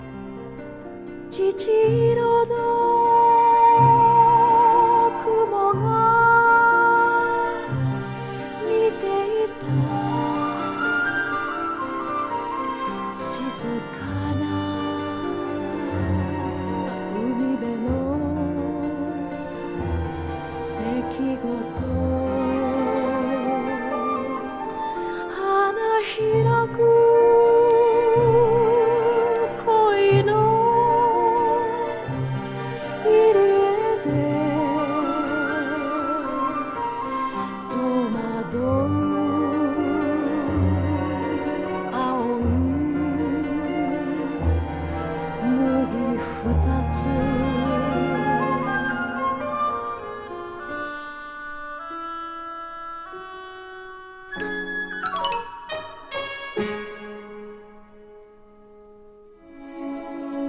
高音質。